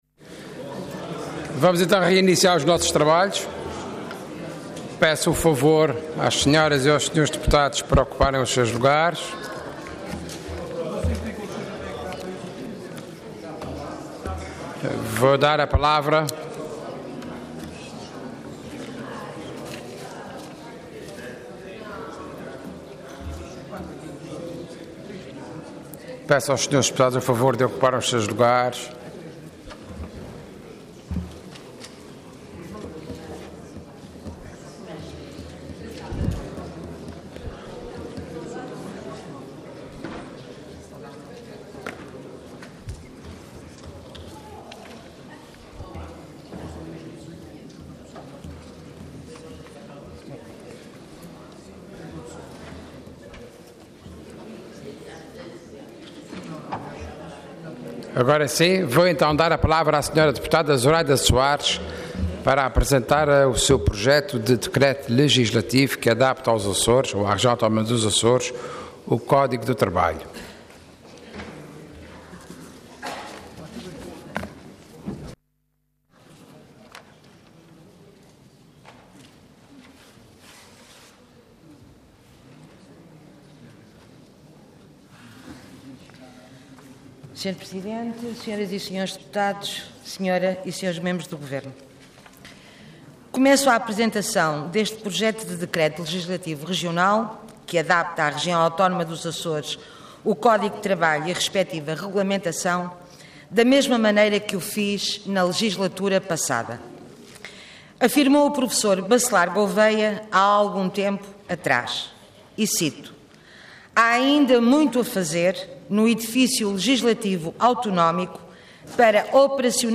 Intervenção Projeto de Decreto Leg. Orador Zuraida Soares Cargo Deputada Entidade BE